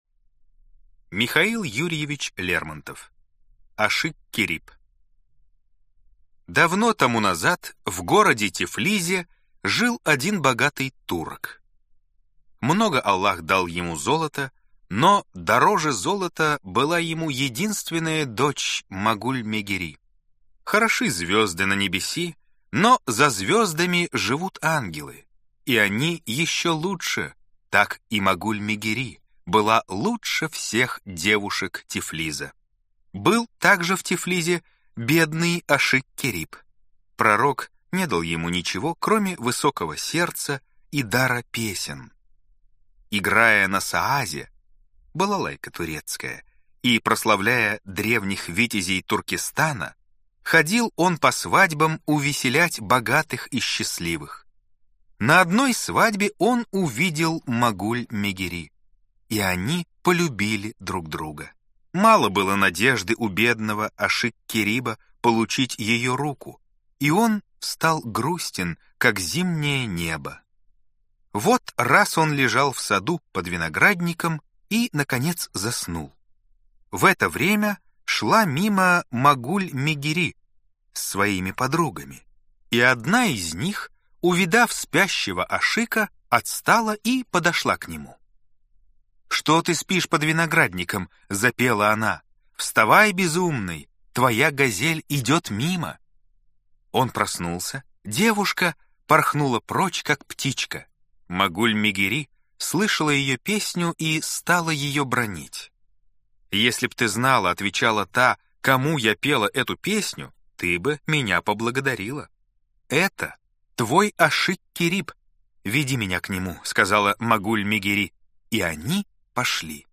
Аудиокнига Ашик-Кериб | Библиотека аудиокниг
Прослушать и бесплатно скачать фрагмент аудиокниги